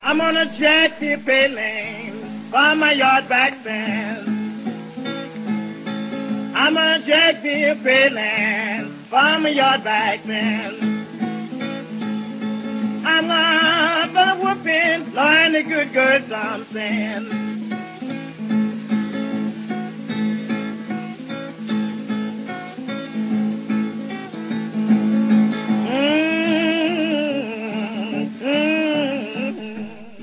блюзовой лирики